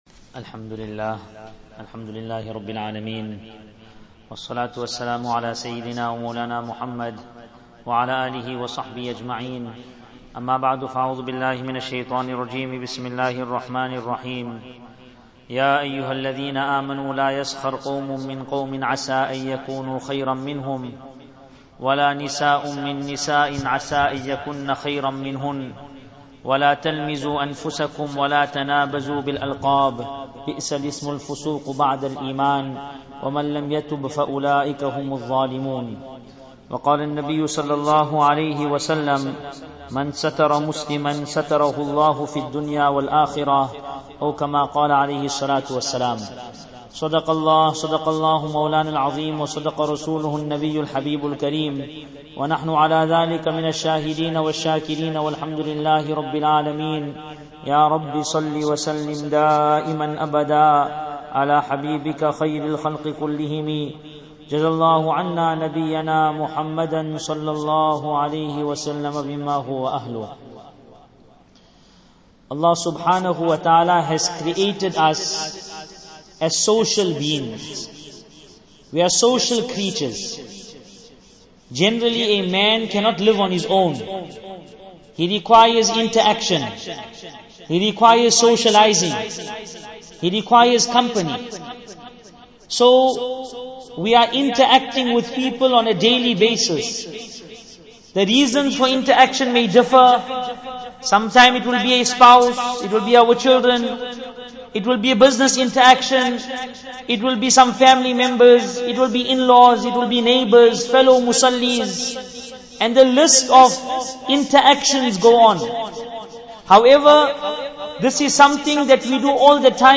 Easily listen to Islamic Lecture Collection